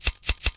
剪刀.mp3